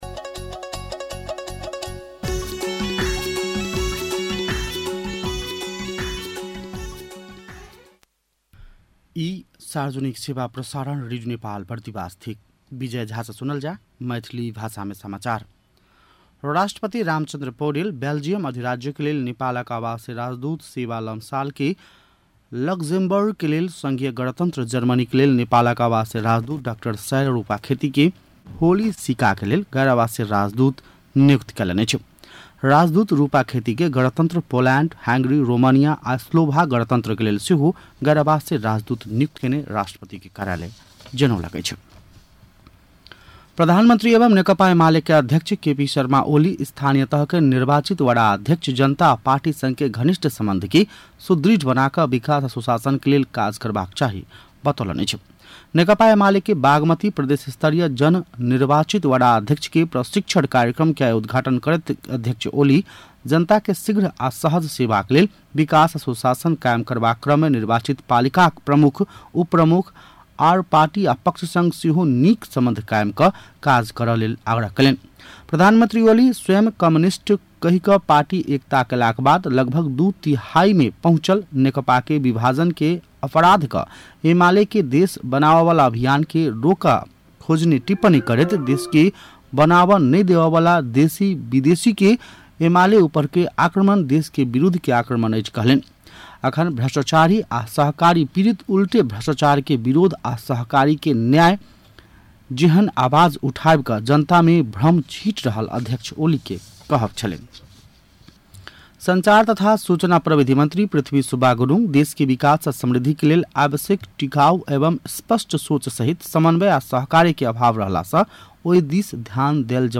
मैथिली भाषामा समाचार : ११ जेठ , २०८२
6.pm-maithali-news-.mp3